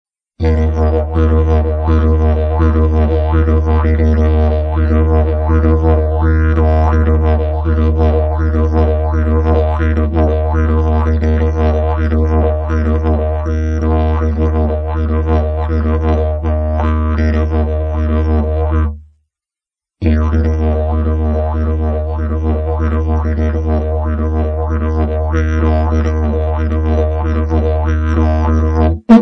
Diese Box hatte etwas andere Parameter als meine bisherigen, angepasst an die Bedürfnisse des künftigen Besitzers: Knalliger Sound, viel Gegendruck.
Erste Lebenszeichen des Rohbaus